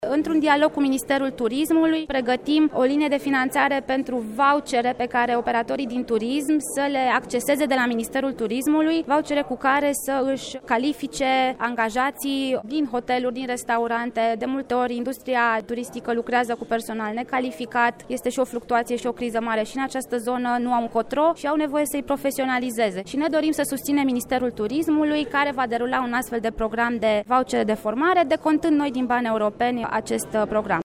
Ministerul Fondurilor Europene pregăteşte două linii de finanţare cu sumele care mai sunt disponibile prin Programul Operaţional Capital Uman pe actualul exerciţiu financiar. Este vorba, de o linie pentru dobândirea de competenţe digitale de către angajaţii IMM-urilor, dar şi de către studenţi şi elevi şi o alta, pentru calificarea personalului hotelier şi din restaurante, a anunţat, ieri, la Braşov, ministrul Roxana Mînzatu: